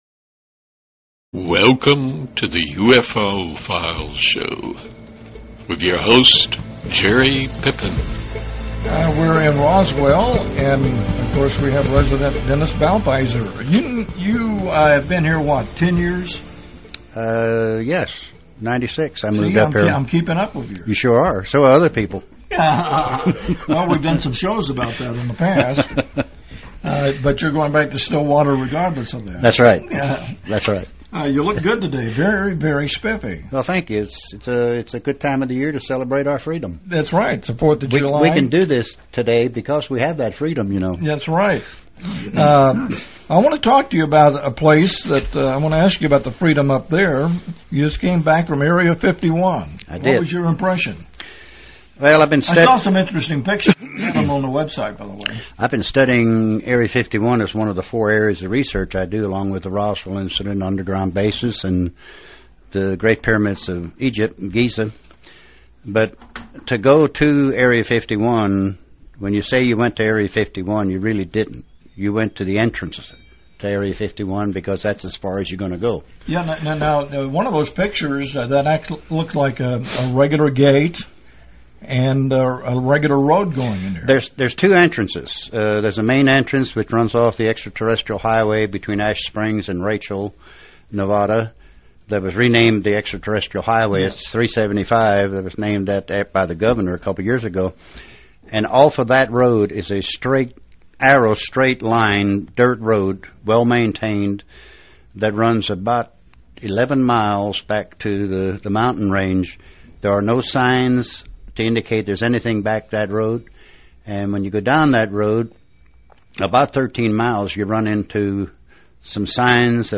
Windows Media Version Part 1 Windows Media Version Part 2 CLICK HERE for more interviews and presentations concerning the 2012 Border Zone UFO Festival